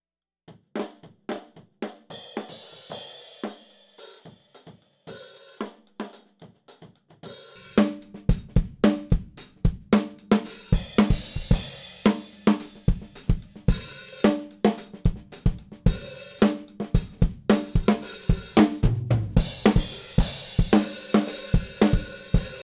The Turner U9SA from the 1940's with its Paper diaphragm; sounds like an old Glenn Miller recording.
In these examples, I am using the Turner Mic set up directly above the snare about 4 ft up.
The Example 1 first plays just the solo'd Turner Mic track, then just the normal drum tracks and at 14sec the turner track is unmuted and you hear them blending. The Turner is just tucked in under the normal tracks to add some MIDs and trashiness to the mix.
These are the unEQ'd and unmixed drums. Just the raw tracks from the tracking session.
drum-mic-example-4416.wav